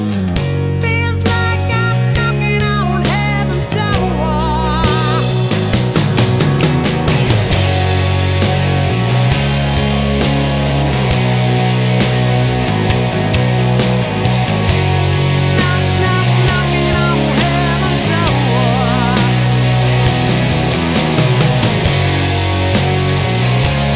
Minus Lead Guitar Rock 5:32 Buy £1.50